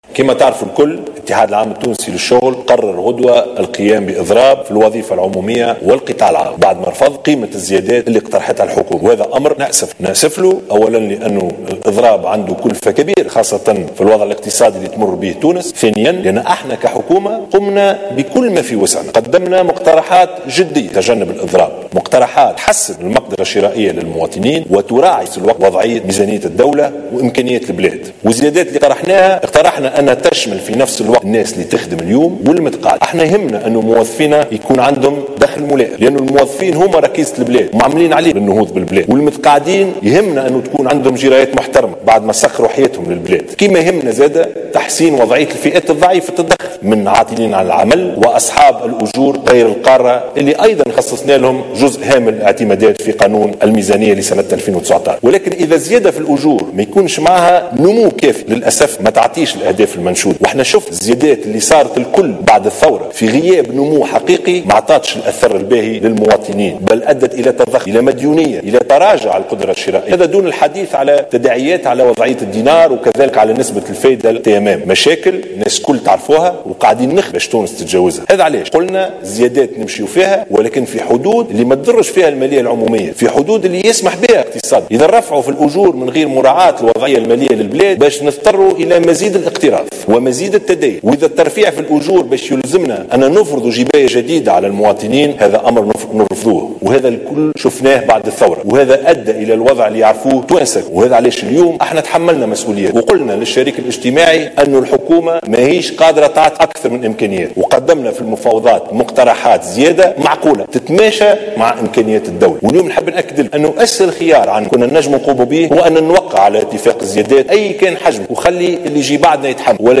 قال رئيس الحكومة يوسف الشاهد خلال كلمة توجه بها للشعب التونسي في النشرة الرئيسية للأنباء على قناة الوطنية الأولى، إن الحكومة قدمت تنازلات للزيادة في أجور أعوان الوظيفة العمومية.